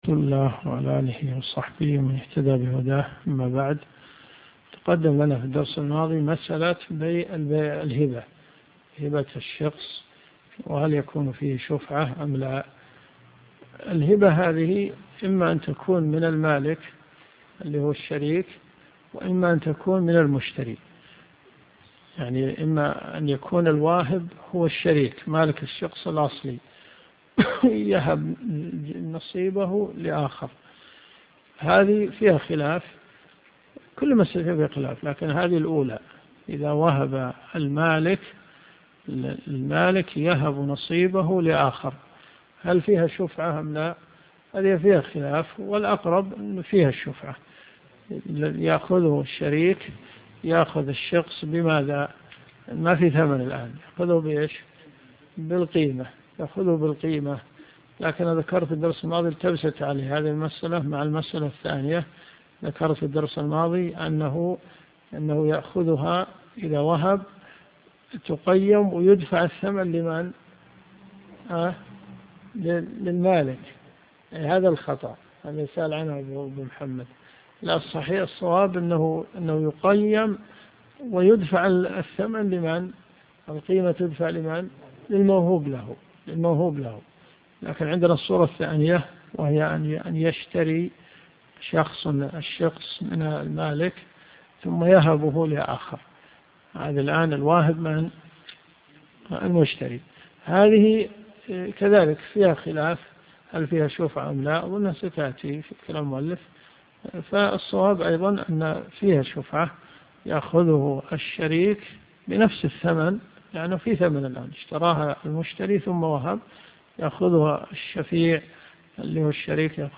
دروس صوتيه ومرئية تقام في جامع الحمدان بالرياض
من ص 635 قوله الرابع -إلى- ص 637 قوله نص عليه - الدرس في الدقيقة 3.30